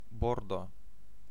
Ääntäminen
Vaihtoehtoiset kirjoitusmuodot shoar Synonyymit support strengthen bolster reinforce buttress prop up seaside seacoast seashore Ääntäminen US UK : IPA : /ʃɔː/ US : IPA : /ʃɔɹ/ Tuntematon aksentti: IPA : /ʃɔː(r)/